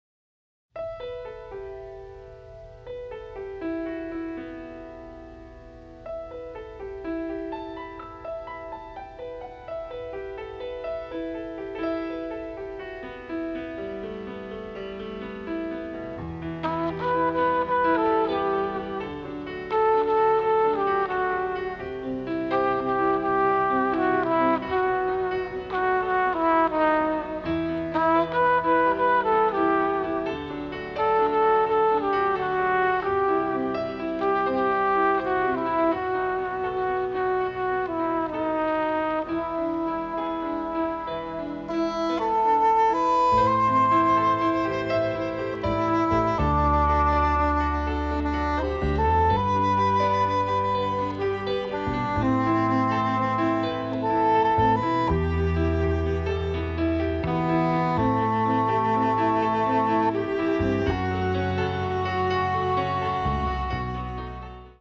tender and powerful score